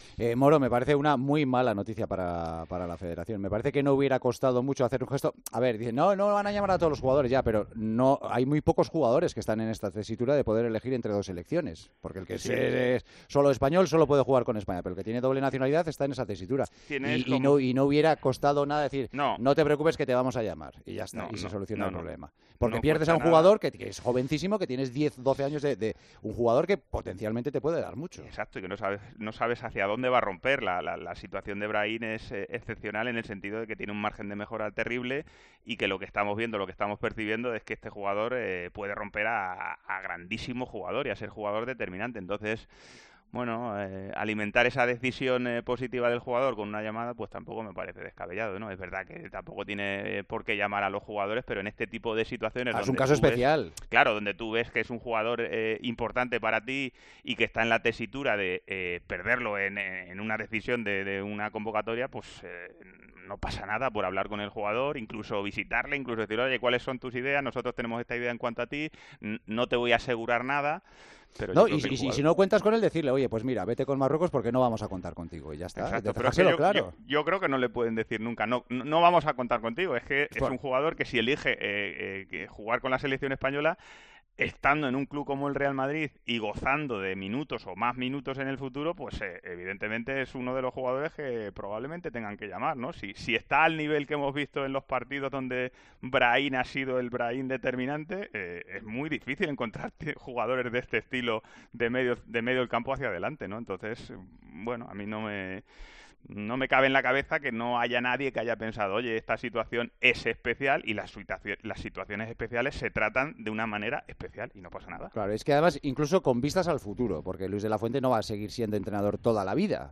Fernando Morientes da su opinión en Tiempo de Juego.